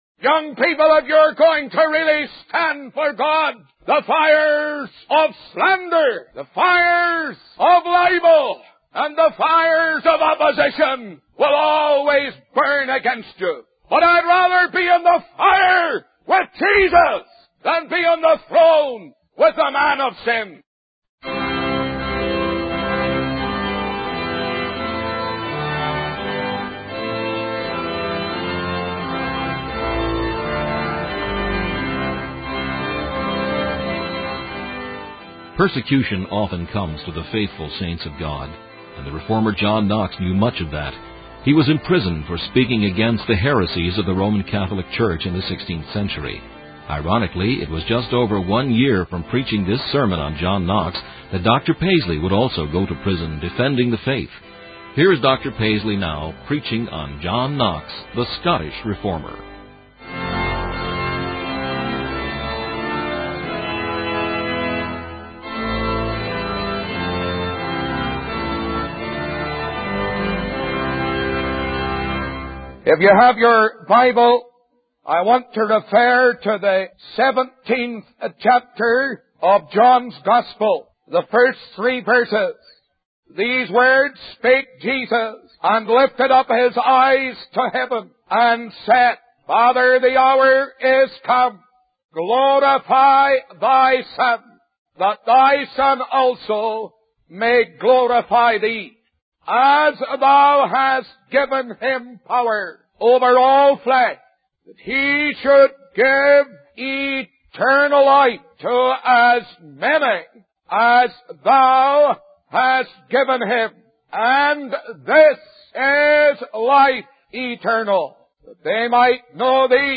In this sermon, the preacher emphasizes the importance of preaching the Gospel of Christ.